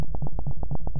#1 I'm attaching two audio files from Pro Tools which were recorded from Kyma's output. The first one sounds like what I get when I trigger my sound with MIDI note #0, which is isn't in my sequence.